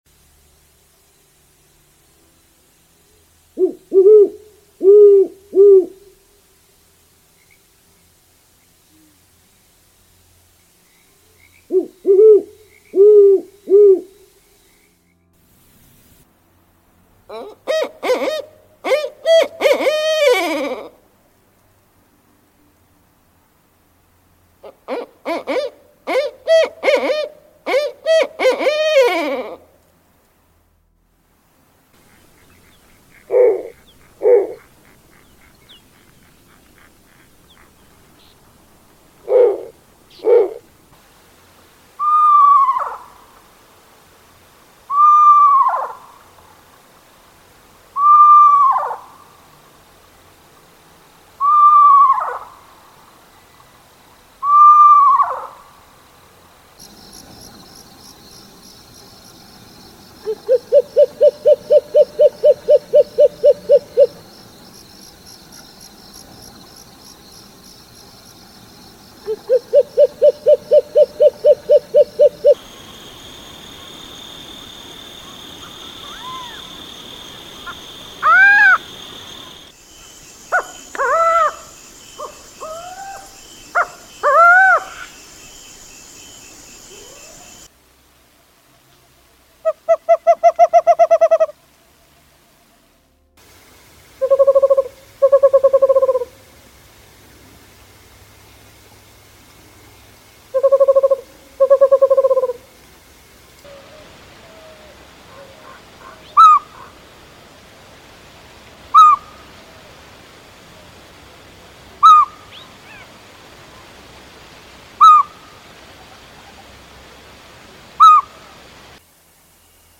How Awesome is some of these Owl sounds.